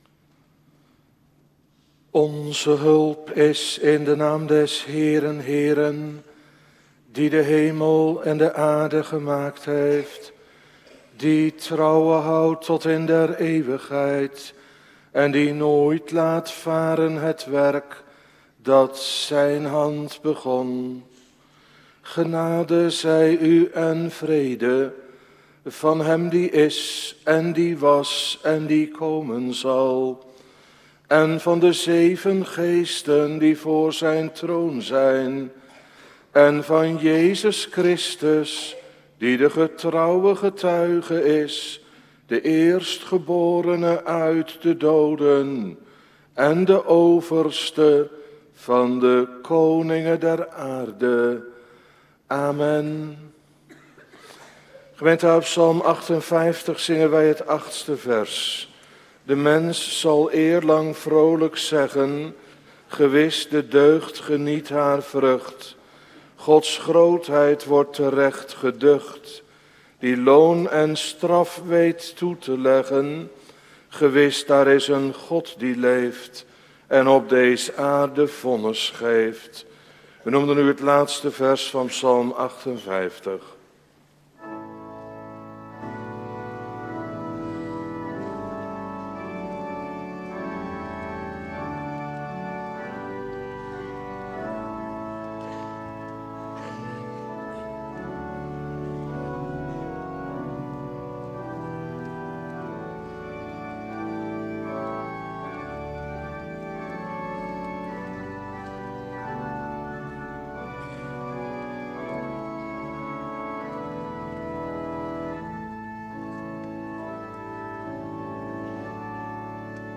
Preken terugluisteren
Morgendienst